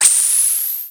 GasReleasing04.wav